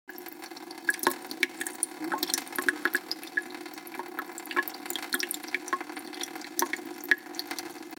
دانلود آهنگ آب 55 از افکت صوتی طبیعت و محیط
دانلود صدای آب 55 از ساعد نیوز با لینک مستقیم و کیفیت بالا
جلوه های صوتی